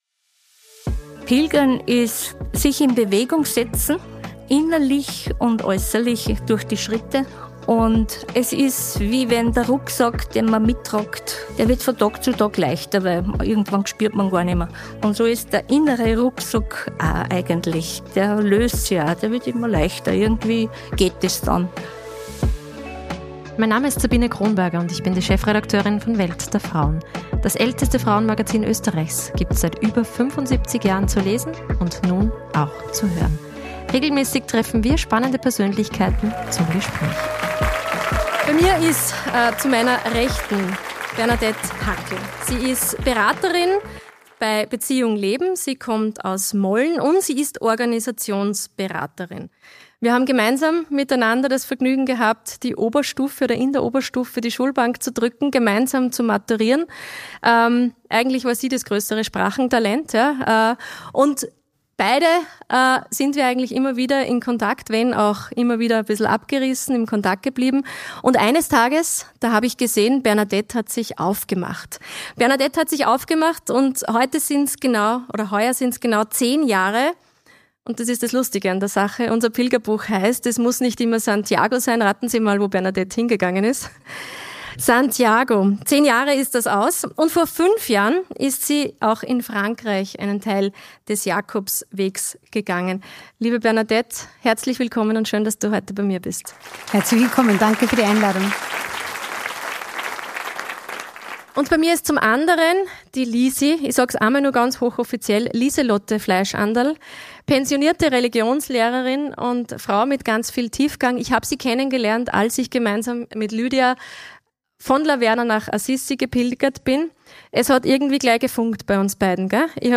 Oder wie man auch per Fahrrad spirituell und pilgernd unterwegs sein kann? Das erzählen die beiden Pilgerinnen auf sympathische Weise.